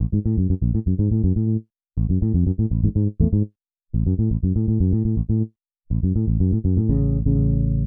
AmajBass.wav